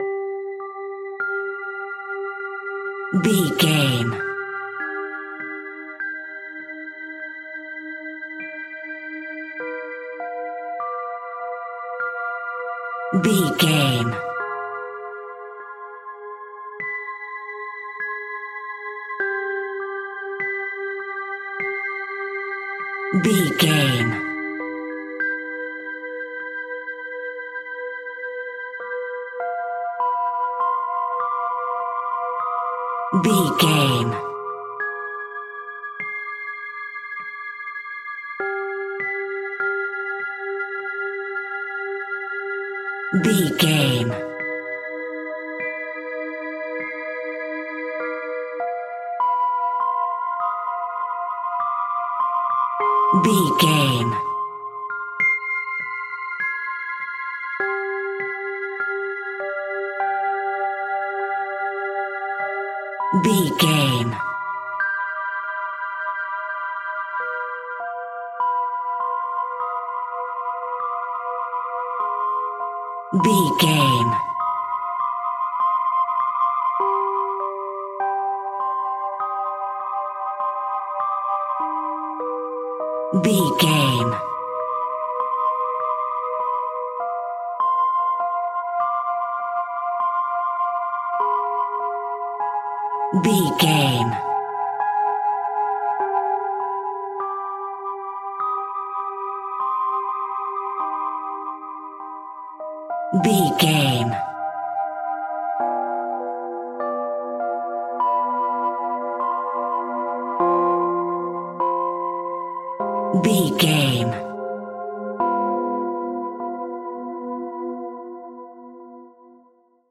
Scary Children's Music Cue.
Aeolian/Minor
Slow
scary
ominous
dark
eerie
piano
electric piano
synthesiser
strings
Horror synth
Horror Ambience